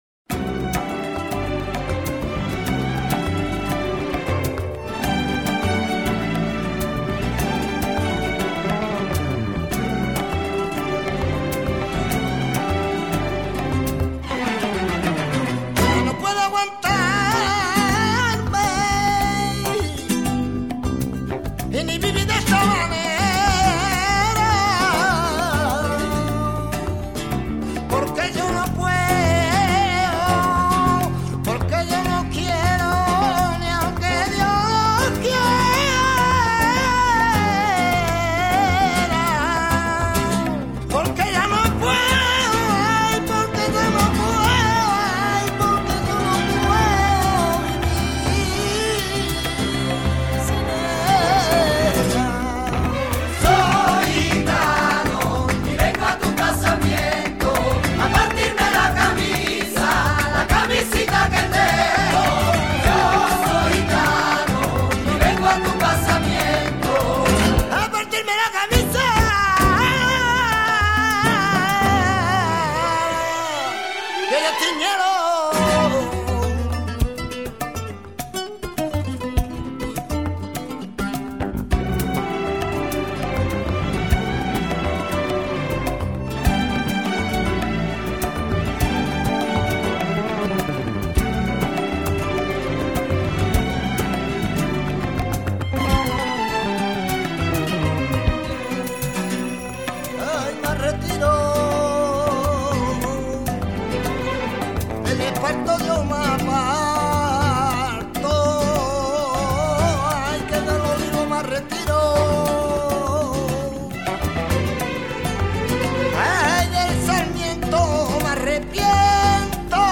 EL CANTE FLAMENCO